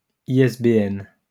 IPA: [iɛsbeɛn], Plural: [iɛsbeɛn]